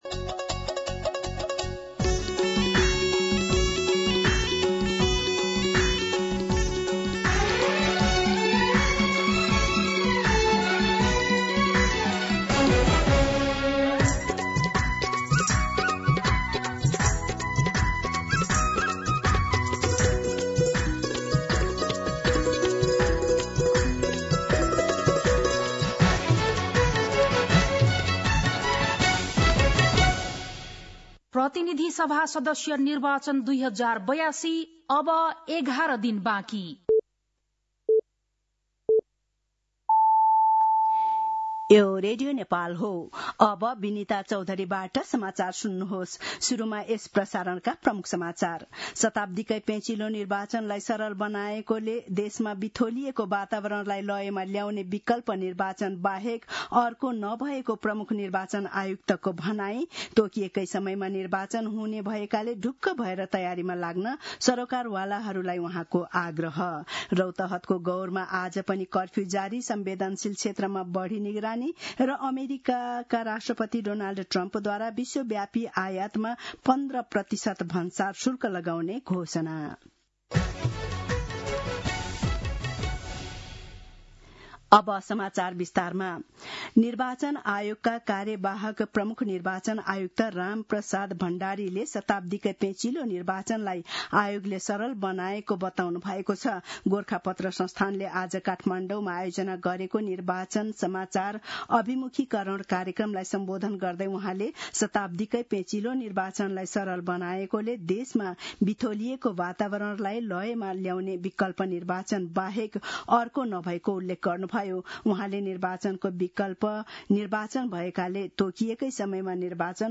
दिउँसो ३ बजेको नेपाली समाचार : १० फागुन , २०८२